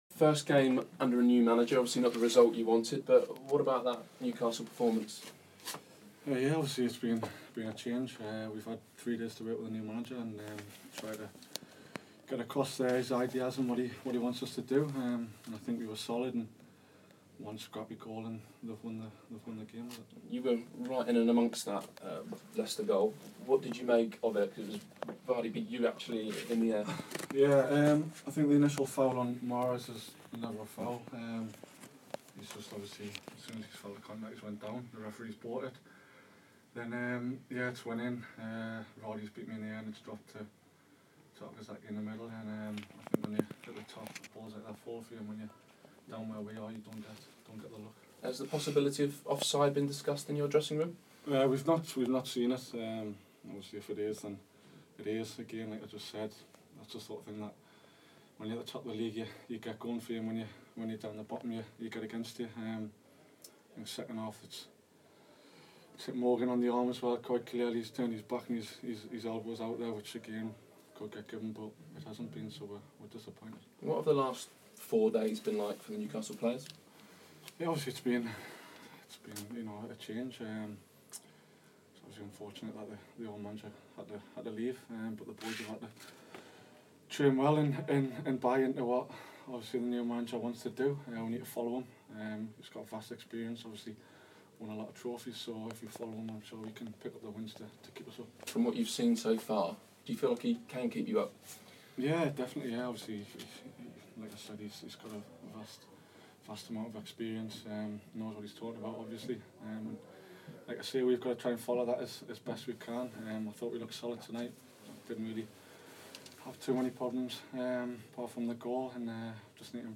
Jack Colback speaks to Sky Sports after Monday's 1-0 defeat at Leicester City.